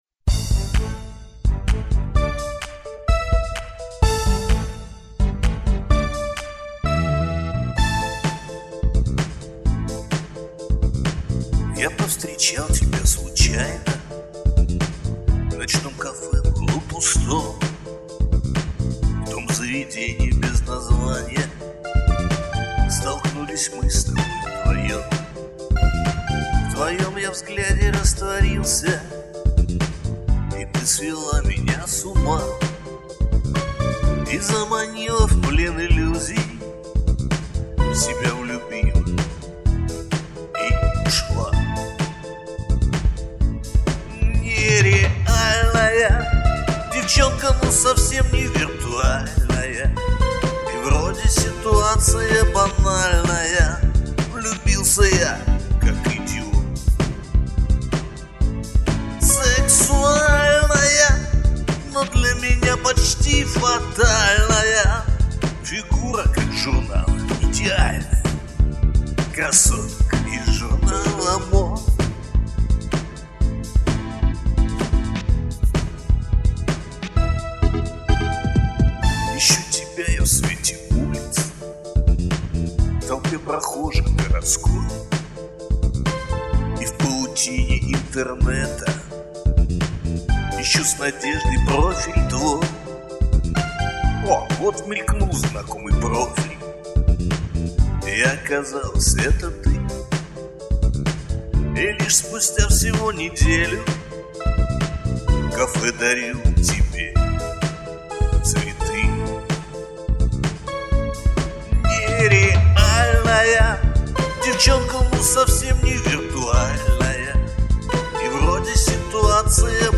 Жанр: Русский поп-шансон